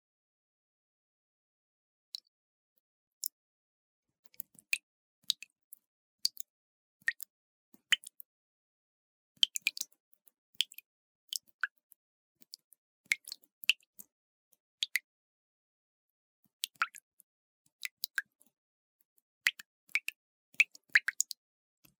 На этой странице собраны звуки весенней капели – мелодичное журчание талой воды, звонкие переливы падающих капель с сосулек.
Звук капель воды при таянии сосулек